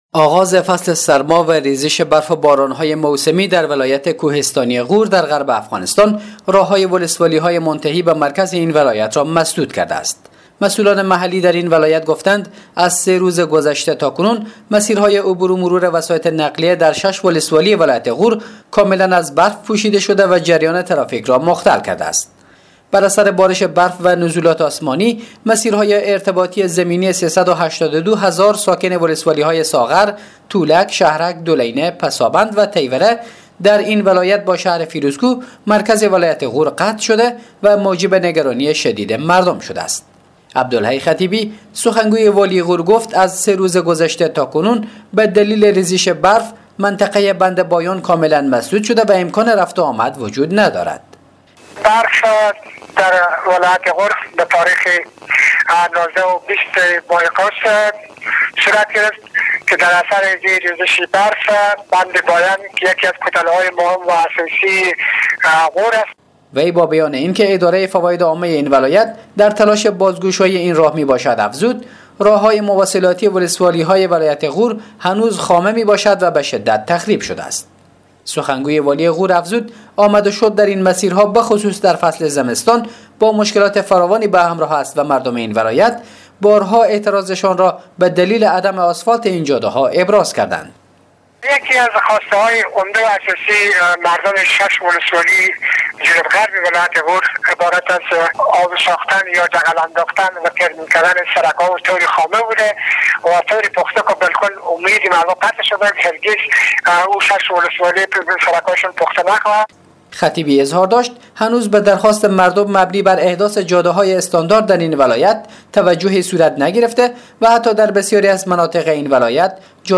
گزارش؛ انسداد راههای ولسوالی ها ولایت غور با مرکز این ولایت